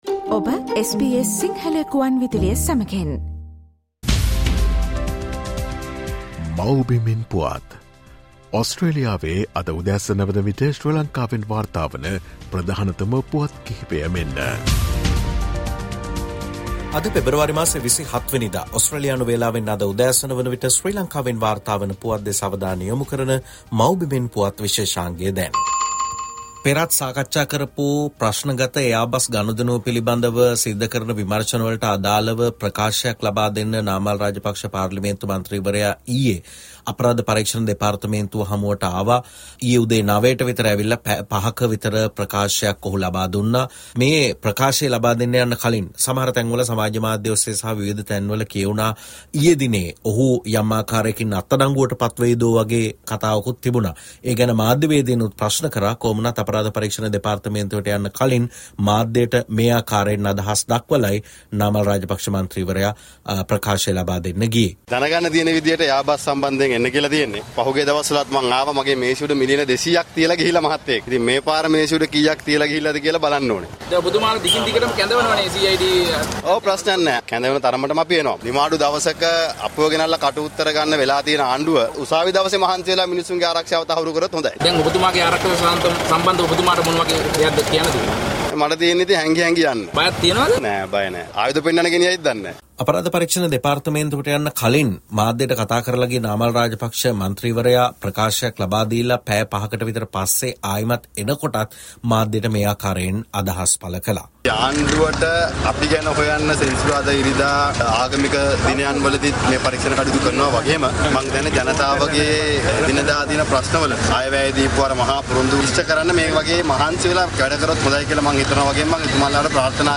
Ranil says 'the UNHRC is adopting a double standard towards Sri Lanka and Ukraine': Homeland news 27 Feb 08:15 Ranil Wickramasinghe_ former president of Sri Lanka.